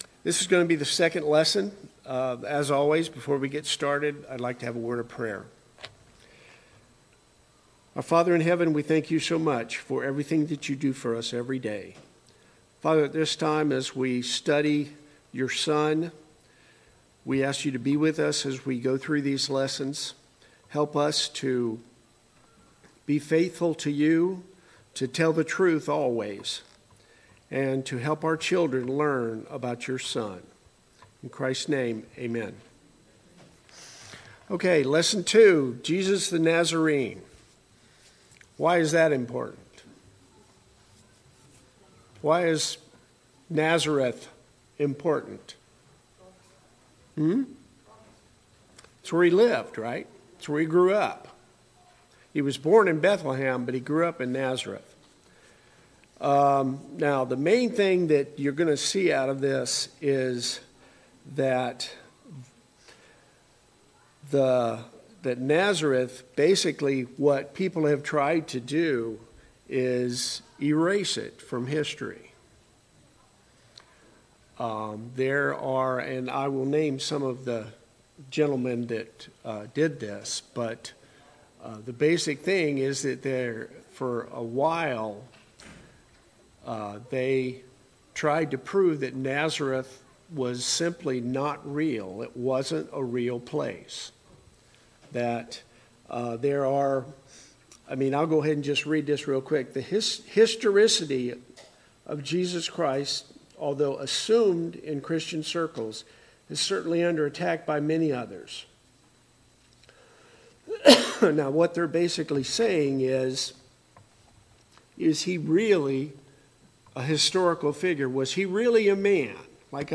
2. Jesus, the Nazarene – 2025 VBS Adult Lesson